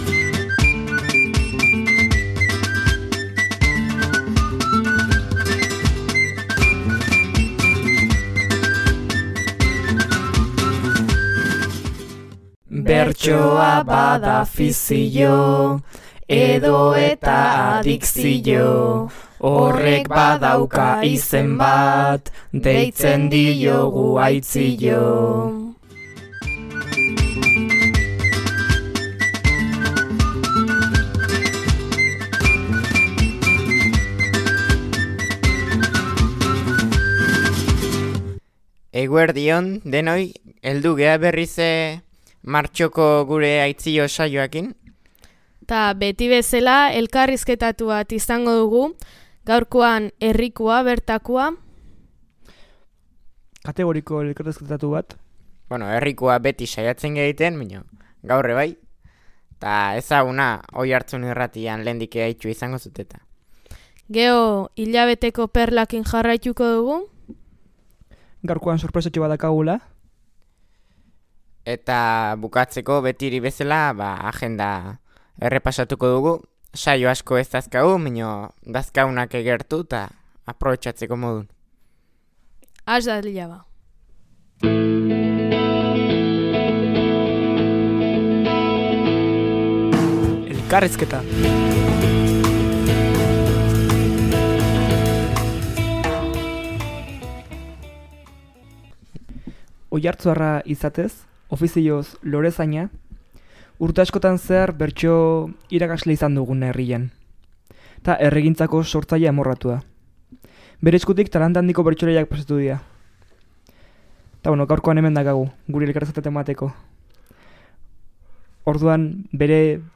Ohi bezala agenda ere izan dute mintzagai eta saioaren laburpena, puntuka egin dute gazteek.